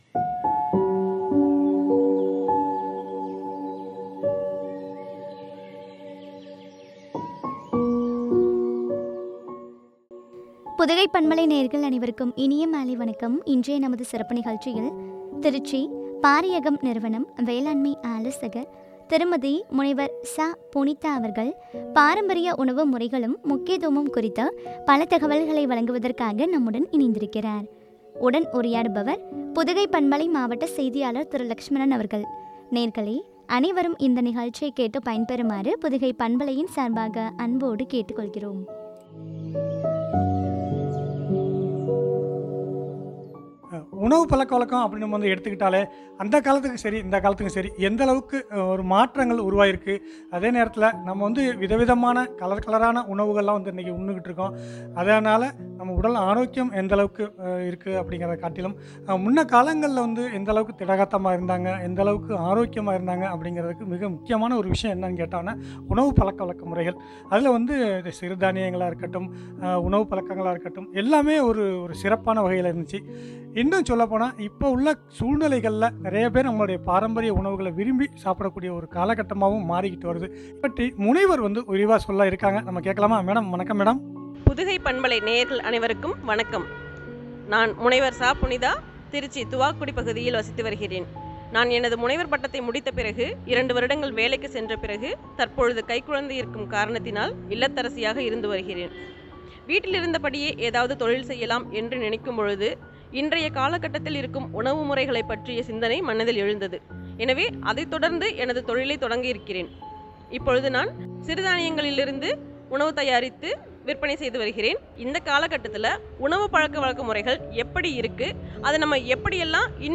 முக்கியத்துவமும் பற்றிய உரையாடல்.